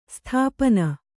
♪ sthāpana